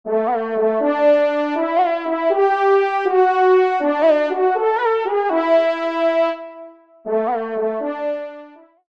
FANFARE
Equipage : Équipage de Valençais